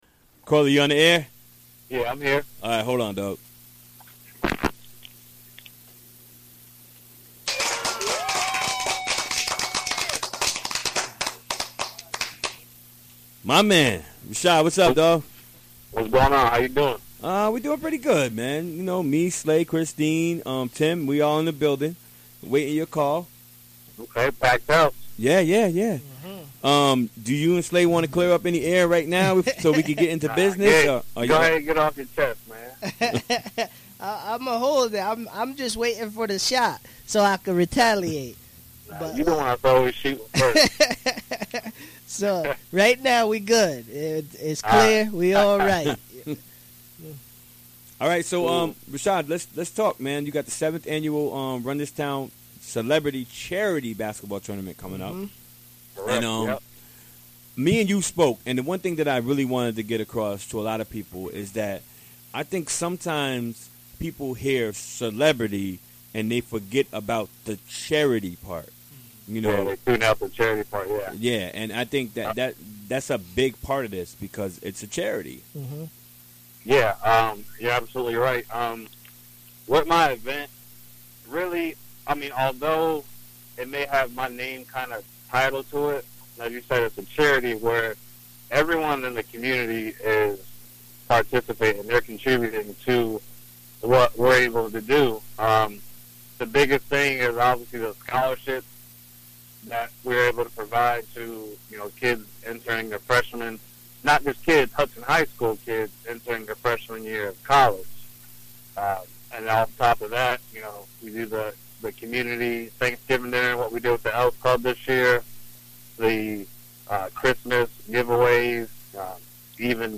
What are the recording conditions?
Recorded during the WGXC Afternoon Show Wednesday, January 25, 2017.